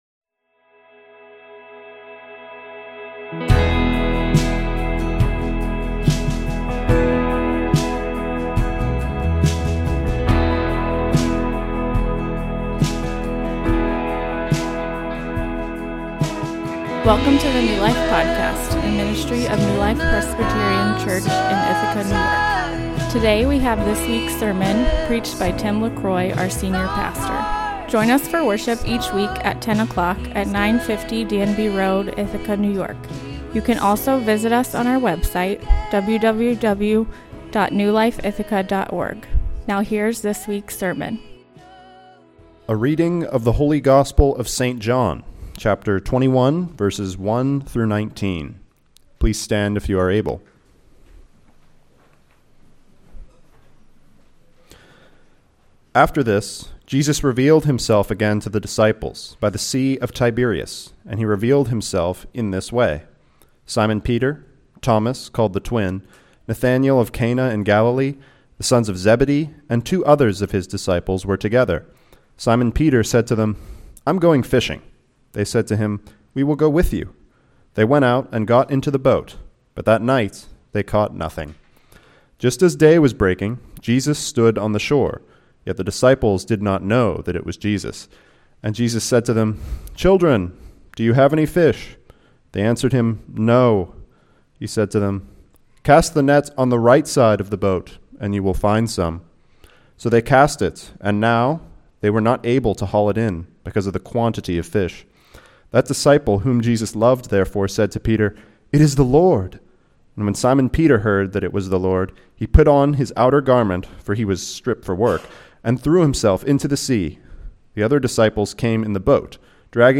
A sermon on the Restoration of Peter from John 21 Quotes: from Braveheart Robert’s Father: I’m the one who’s rotting, but I think your face looks graver than mine.